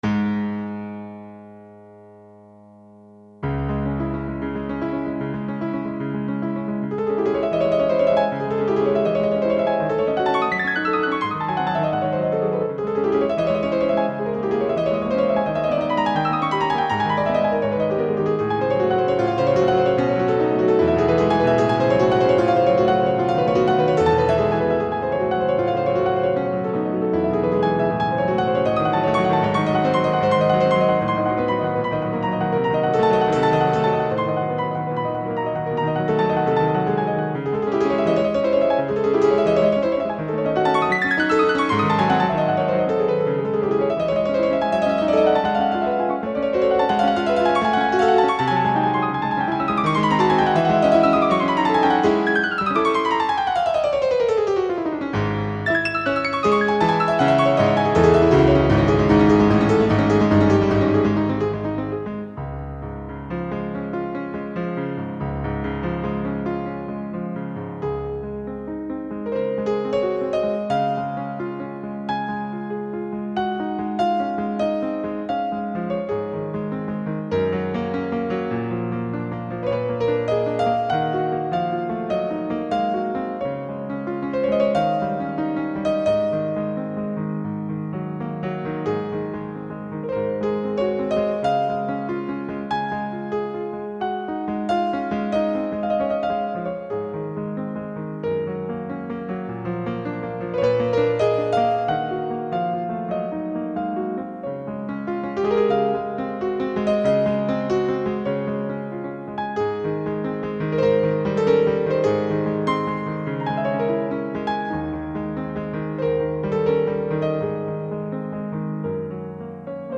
＜注意＞ＭＰ３データはＳＣ８８５０で演奏したものです
データもピアノ曲として仕上げました。